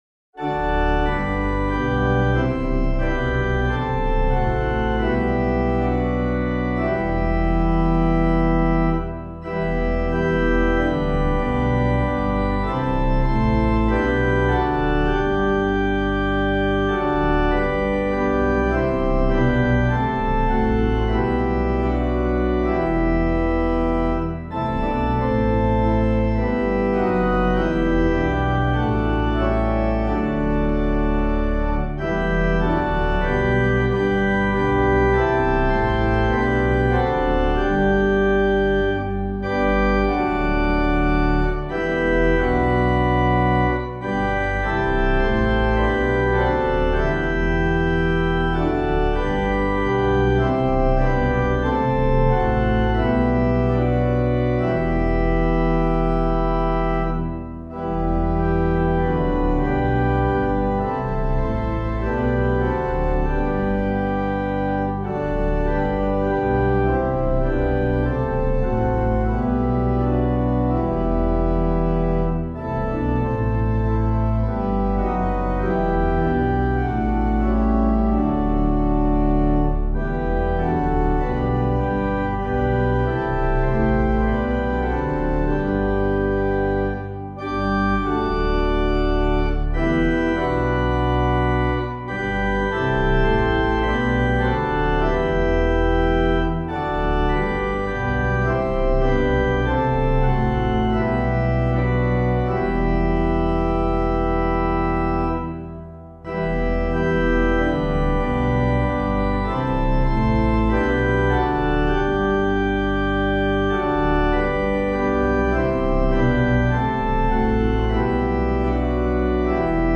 Composer:    Chant, Mode I; Processionale, French, 15th cent.;